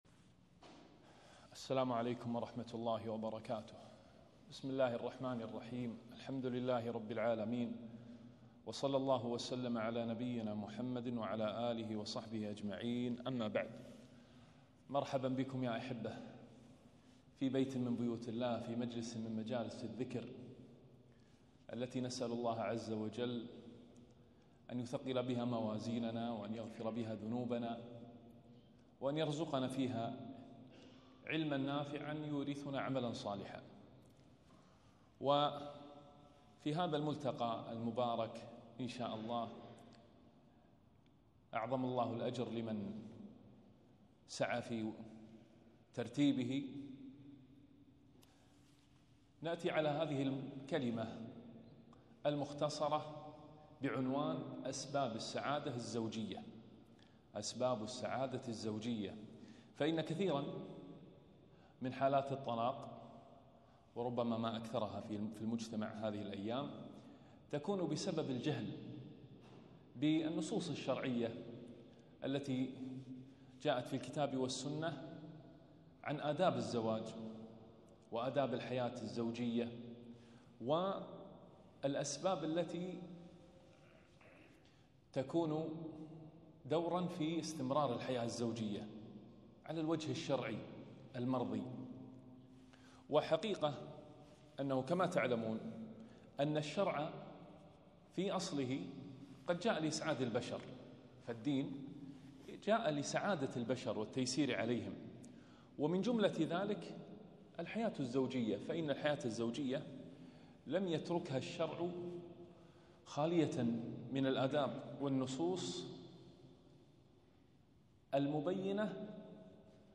محاضرة - أسباب السعادة الزوجية